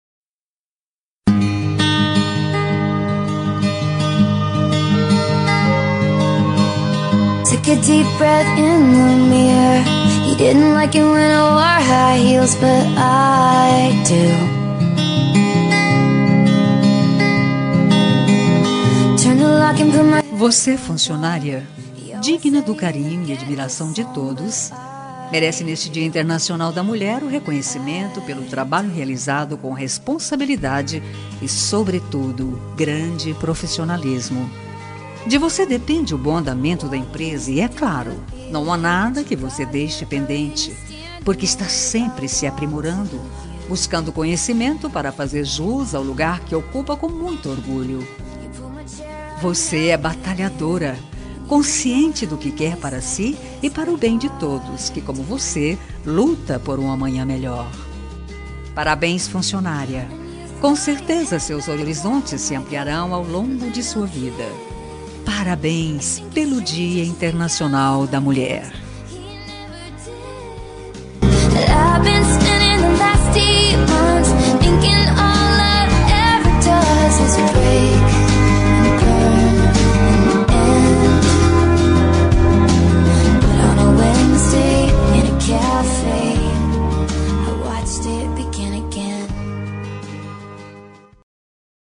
Dia das Mulheres Para Amiga – Voz Masculina – Cód: 5371